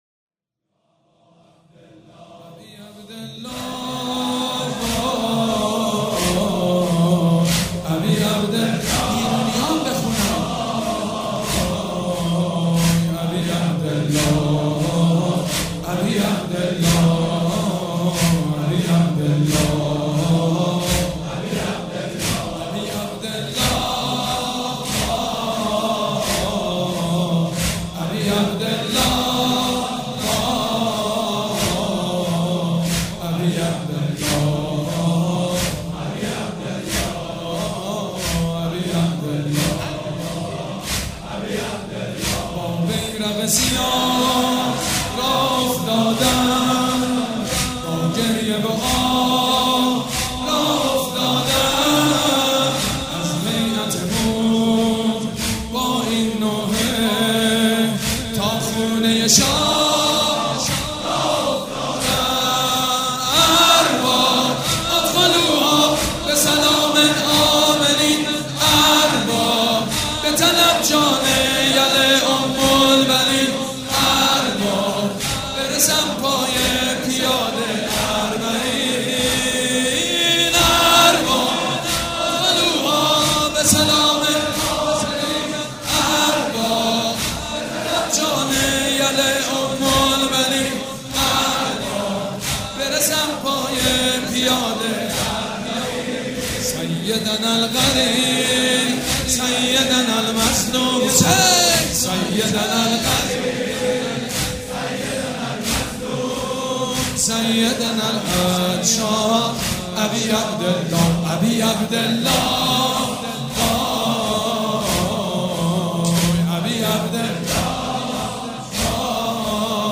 مداح حاج سید مجید بنی فاطمه
مراسم عزاداری شب عاشورا